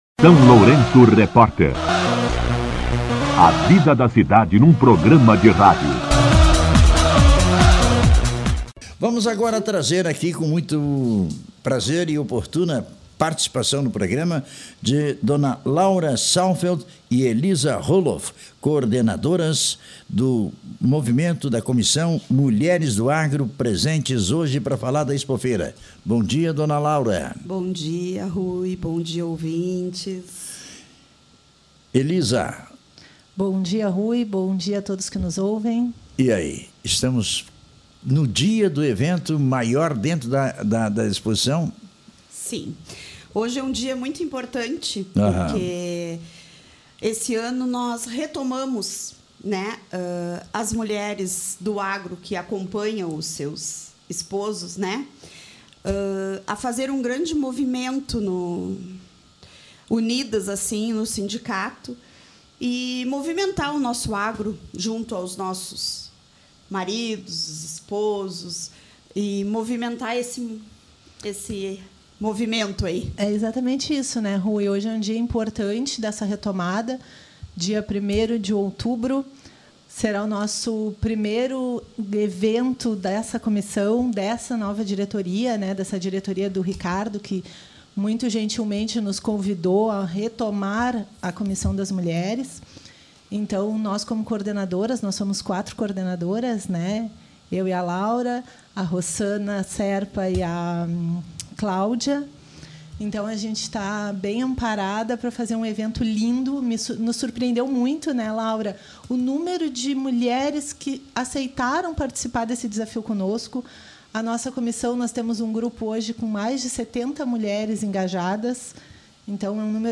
Mulheres-Agro-Expo.mp3